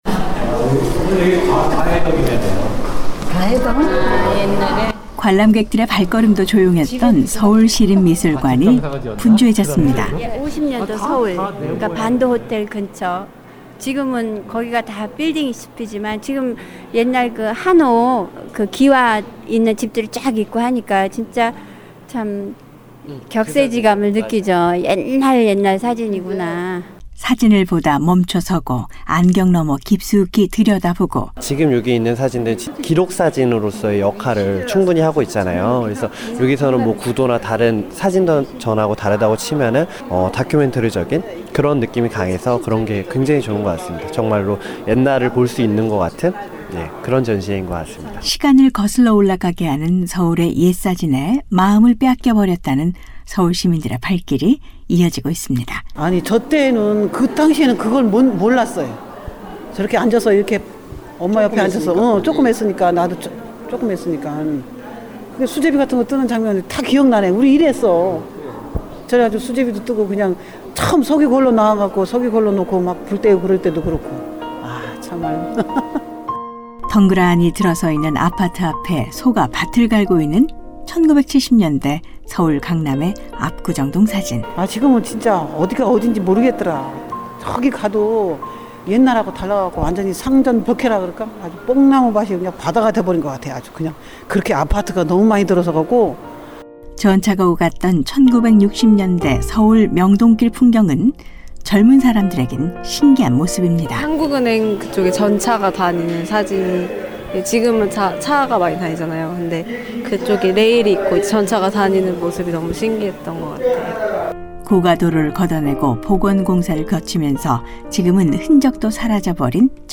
지금 서울시내 곳곳에서는 서울의 옛 모습을 한 자리에서 볼 수 있는 사진축제가 열리고 있습니다. 전문 사진작가들이 찍어둔 서울의 옛 사진들도 볼거리지만, 평범한 서울시민들이 가족 앨범 속에서 꺼내 준 추억의 사진들은 시민과 함께 하는 사진축제의 의미를 더하고 있다는데요. 30년 ~40년, 시간을 거슬러 올라가게 한다는 특별한 전시회 '서울사진축제' 현장으로 가보겠습니다.